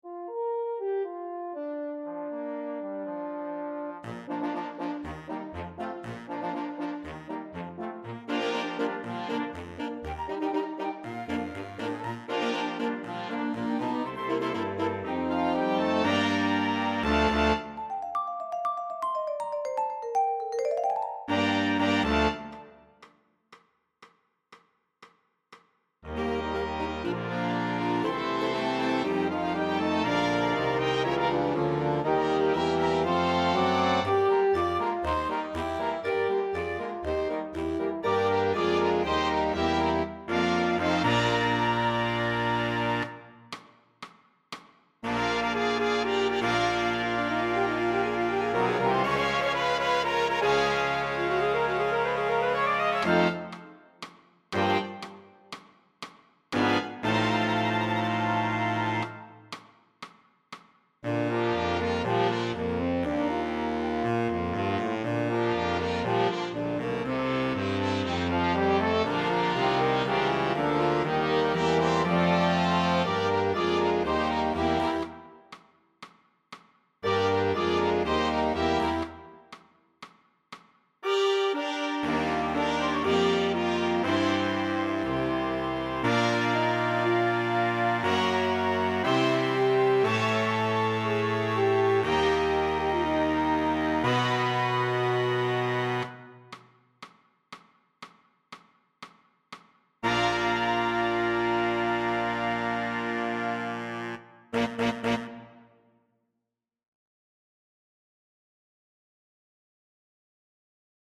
Winds